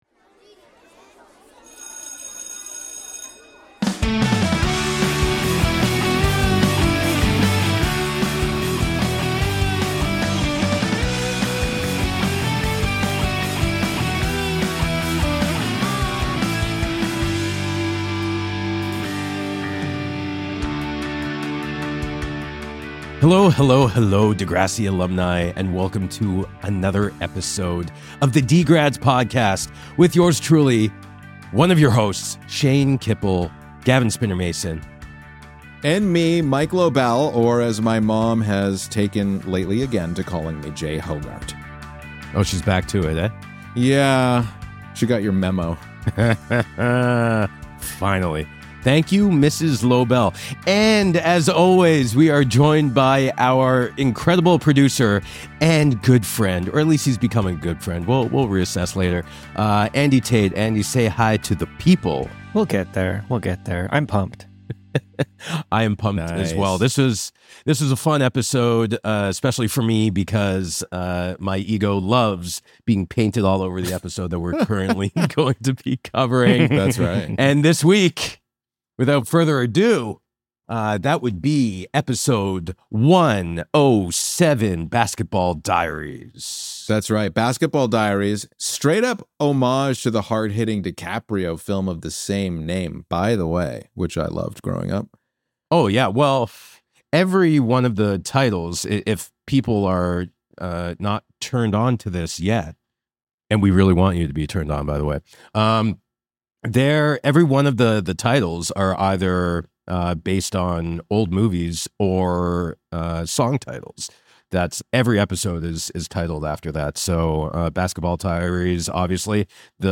Credits: Hosts - Shane Kippel and Mike Lobel Producer